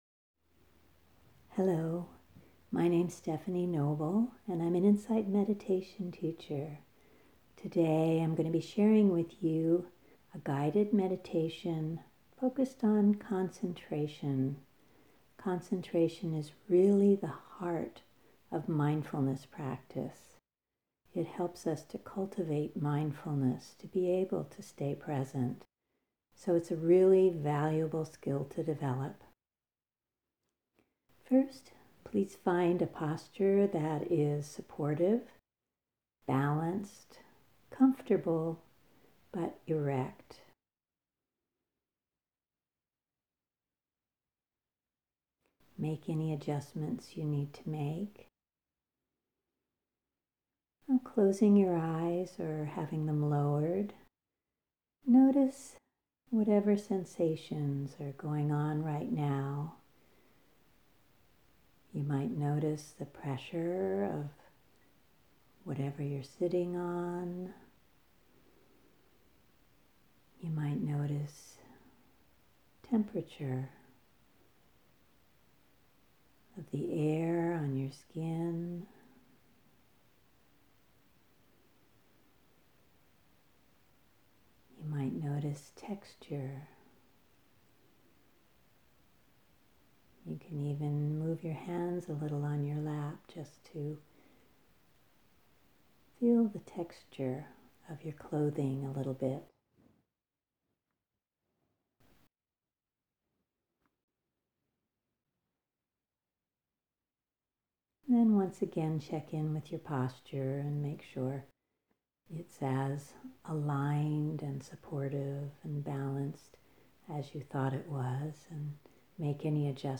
GUIDED MEDITATION ON THE BREATH https
concentration-breath-bell.mp3